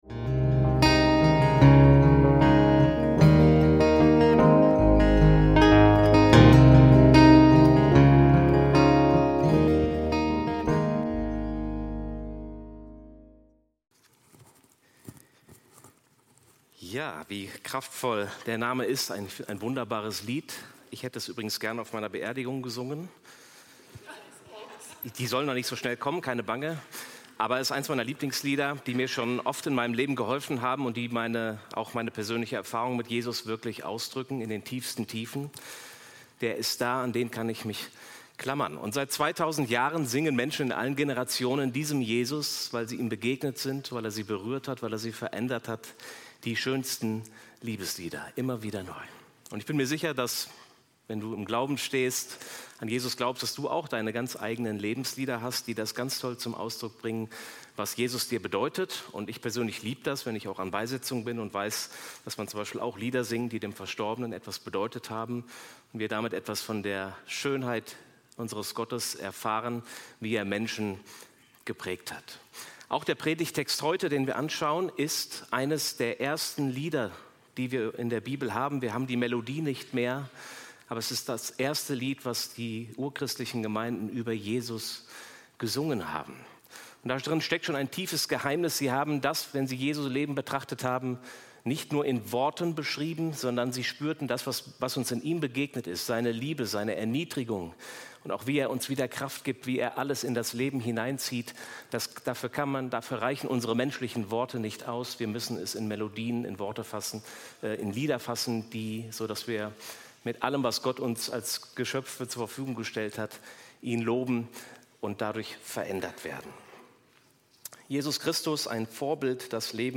Jesus Christus - Vorbild, das Leben berührt & verändert - Predigt vom 13.04.2025